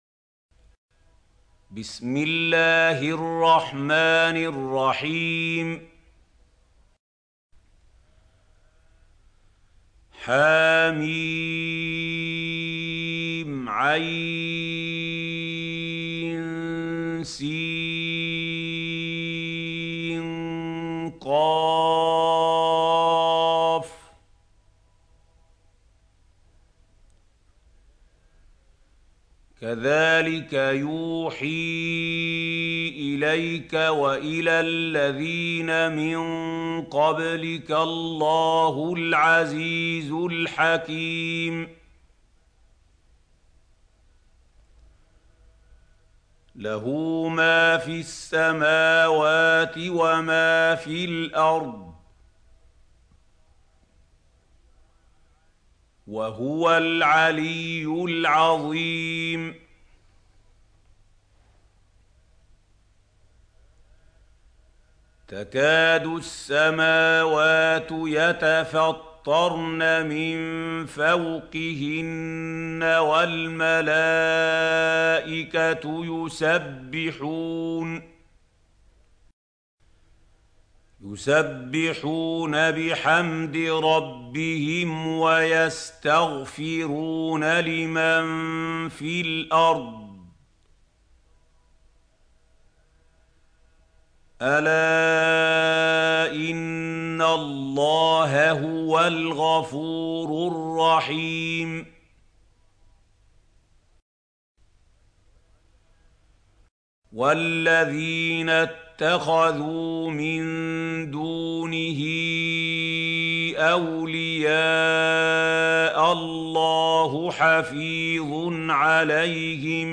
سورة الشورى | القارئ محمود خليل الحصري - المصحف المعلم